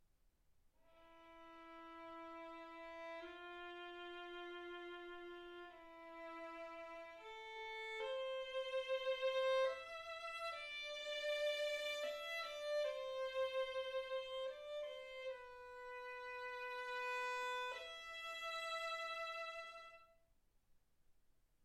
Hegedű etűdök Kategóriák Klasszikus zene Felvétel hossza 00:22 Felvétel dátuma 2025. december 8.